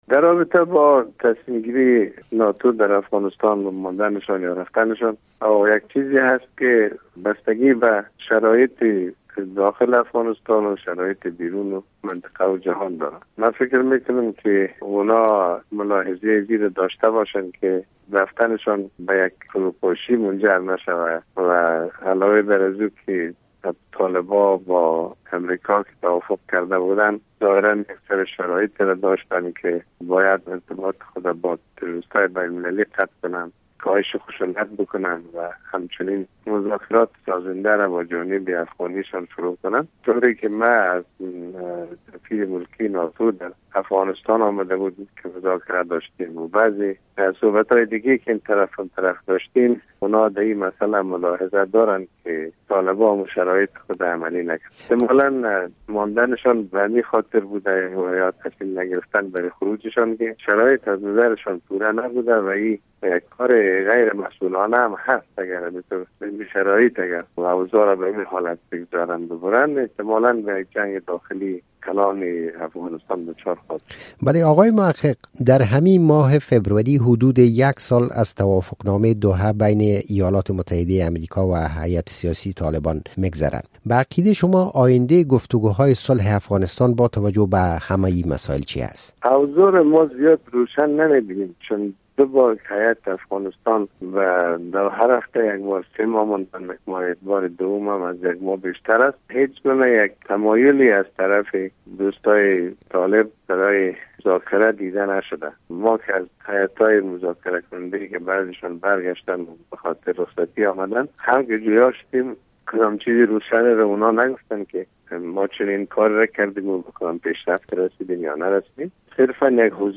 گفت‌وگو با محمد محقق؛ چرا ناتو در مورد خروج از افغانستان تصمیم نگرفت؟